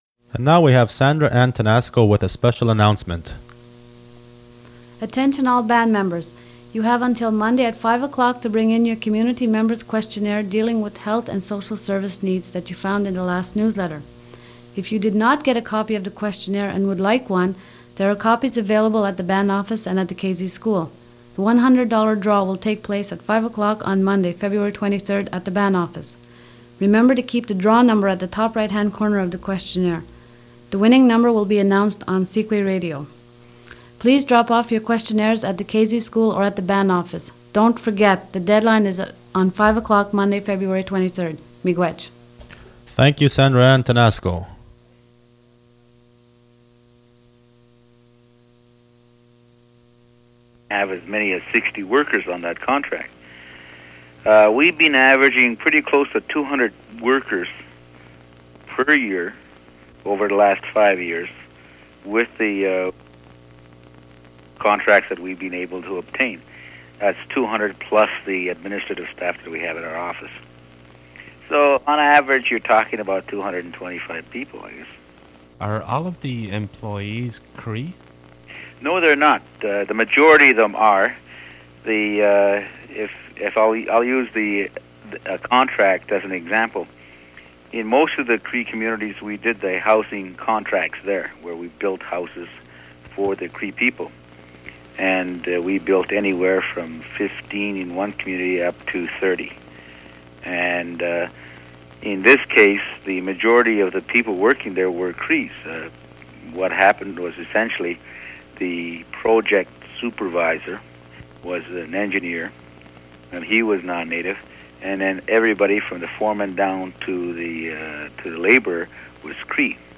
Fait partie de Reminder for band members and interview about the Cree Construction Company